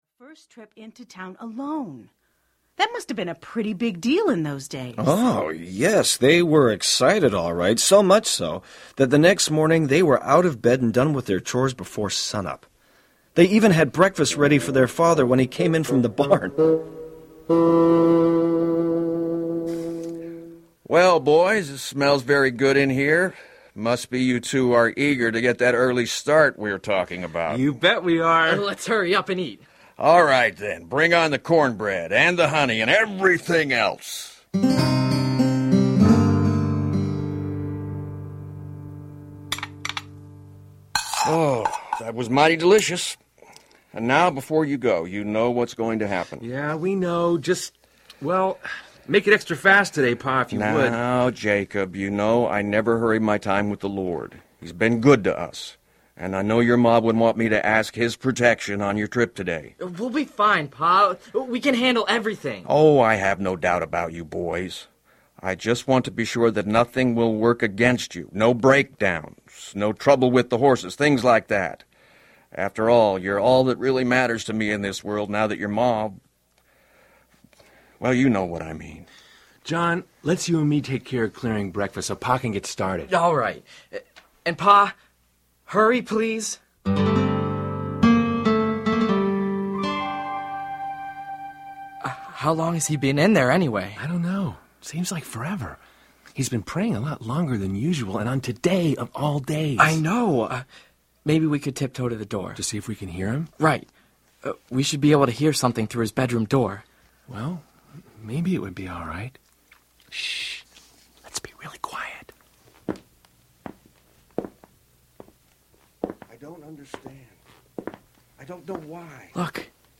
A Complete Audio Cast
5.53 Hrs. – Unabridged